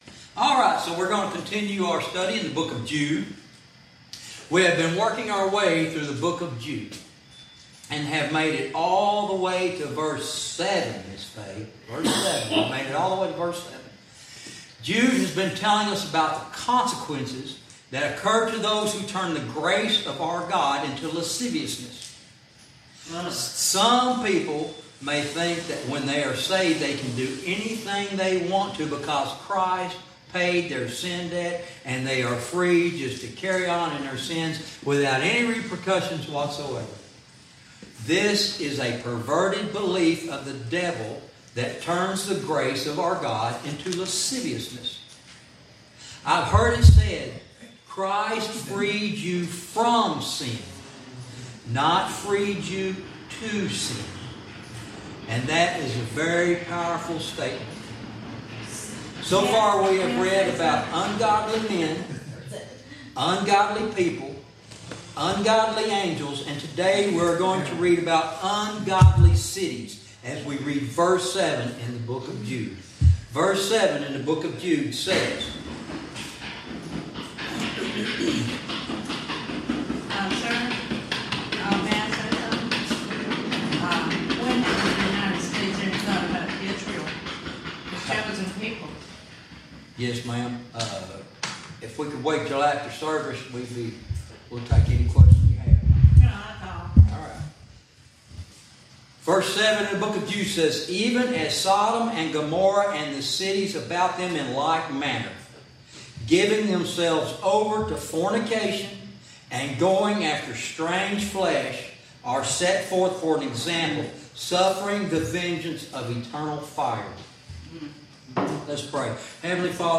Verse by verse teaching - Lesson 19